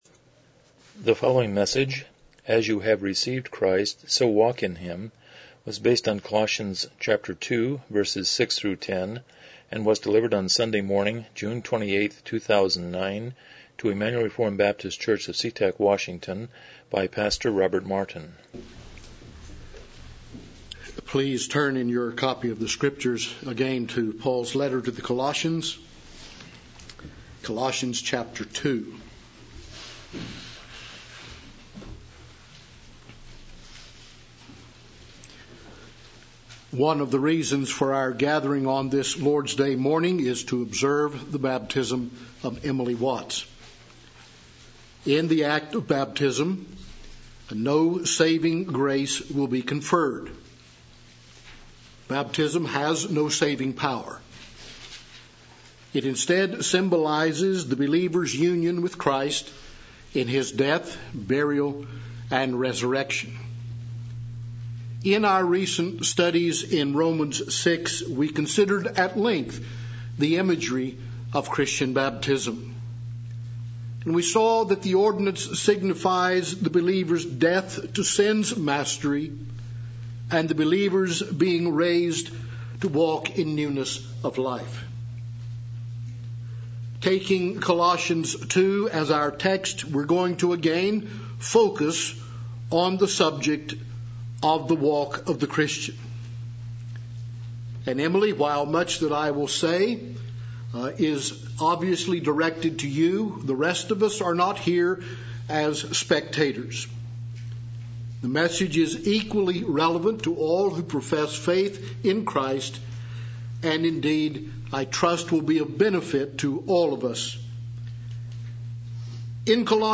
Colossians 2:6-10 Service Type: Morning Worship « 36 What is a Covenant?